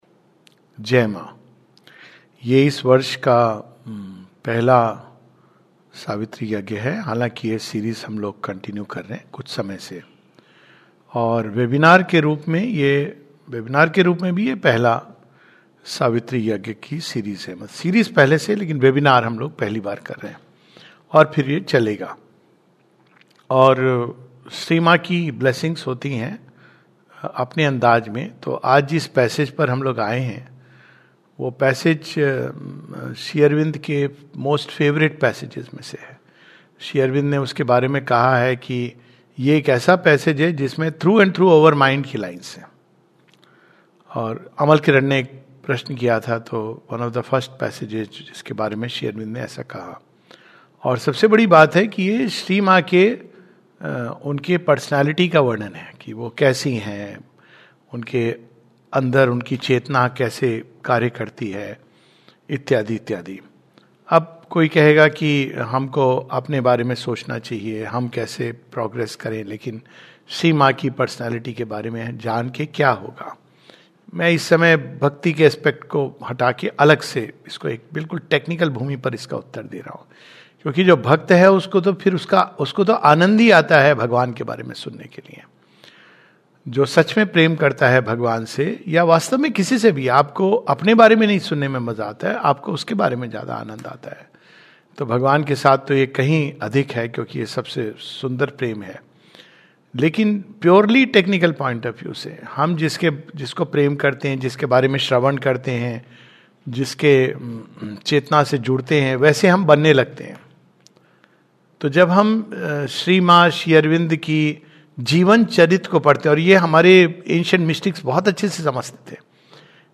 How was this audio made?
The following passages were read in today's webinar: 'Well might he find in her his perfect shrine.